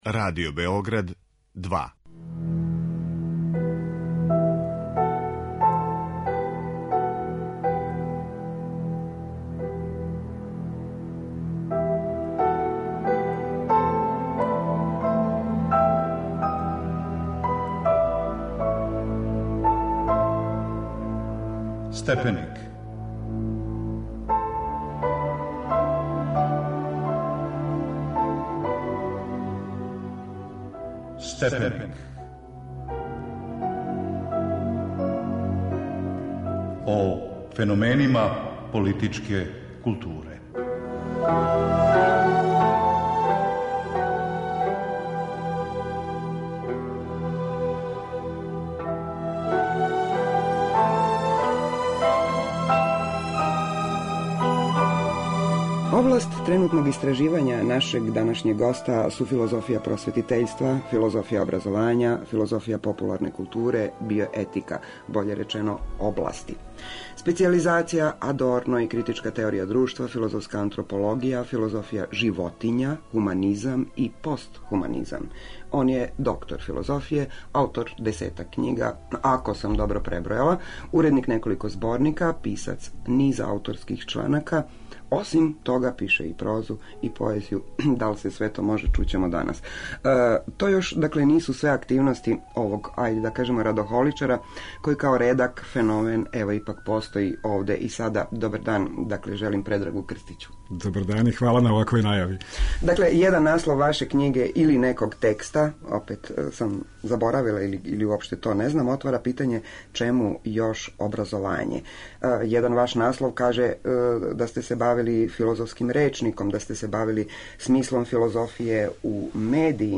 О просвећености и просветитељском пројекту разговарамо